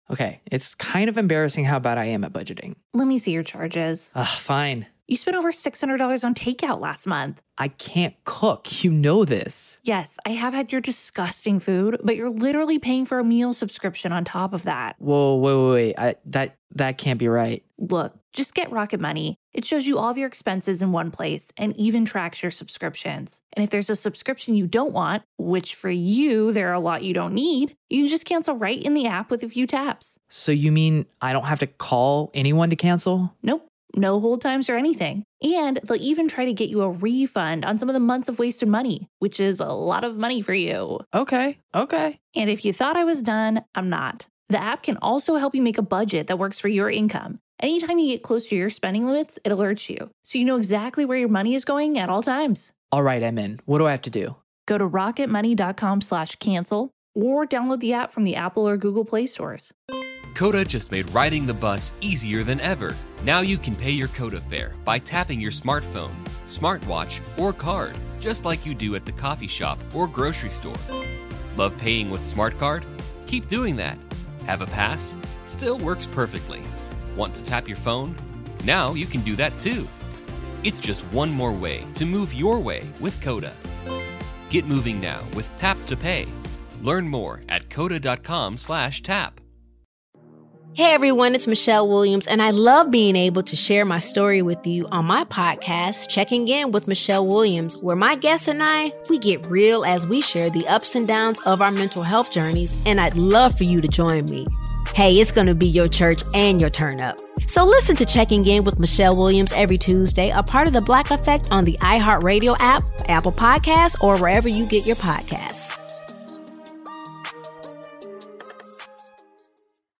Listen to Part 2 of 2 as Scott Ferrall call an Atlanta Thrashers game against the Pittsburgh Penguins in March of 2000